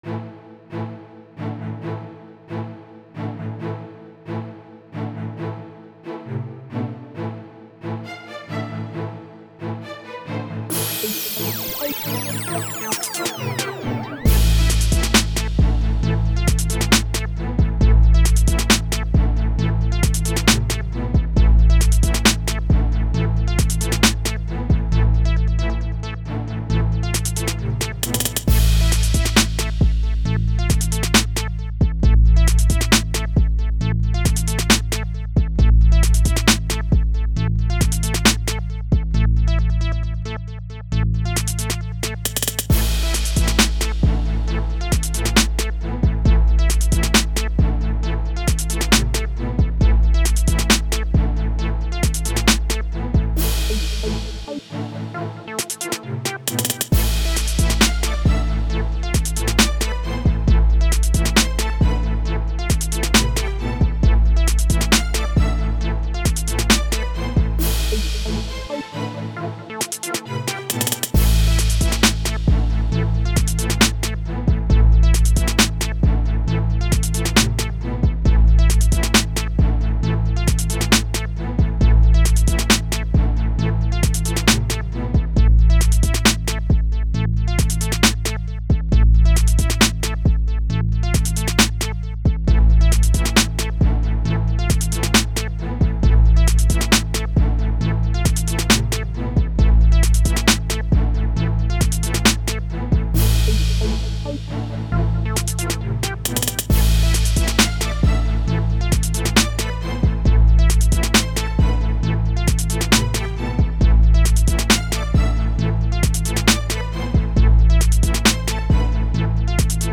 Скачать Минус
Стиль: Rap